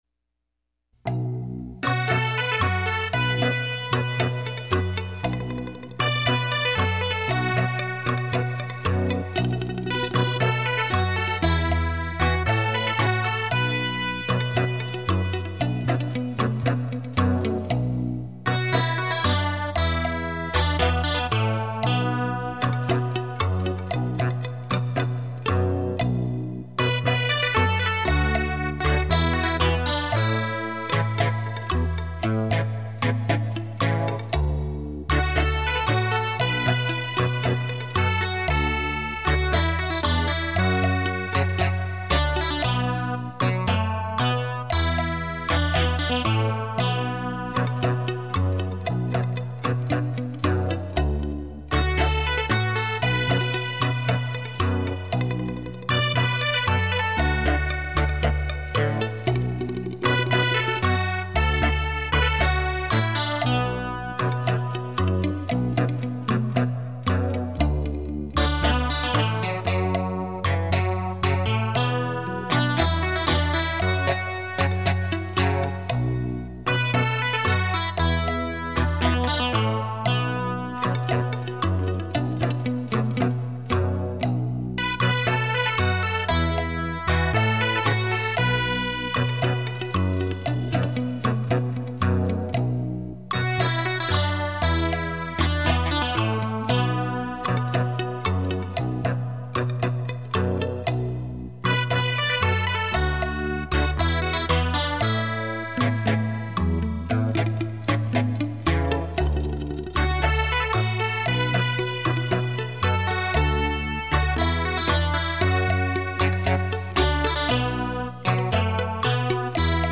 汽车音响测试碟
立体音声 环绕效果